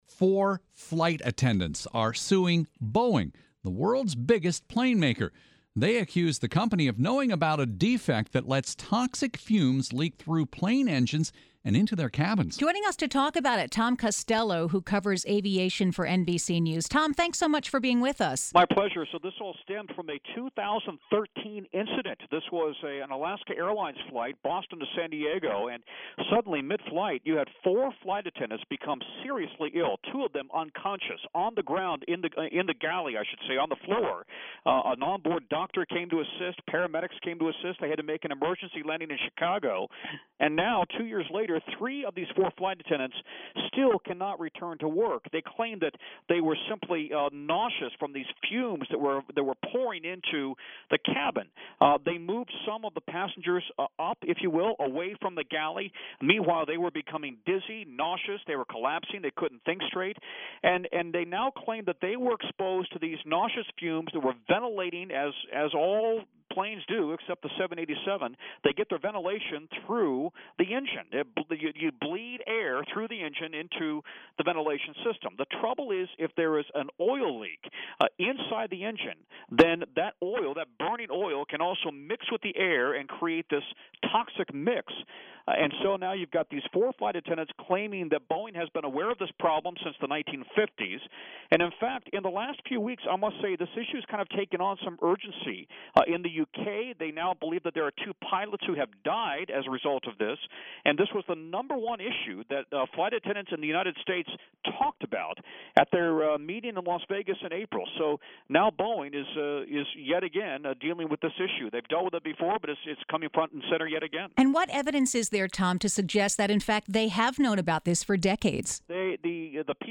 February 13, 2026 | Is the air in planes harmful? (NBC News' Tom Costello talks with WTOP)